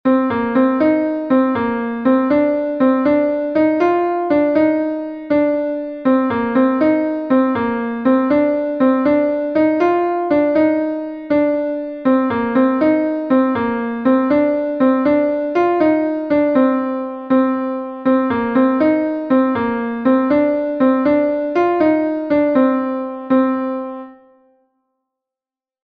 Gavotenn Lokmaria-Berrien est un Gavotte de Bretagne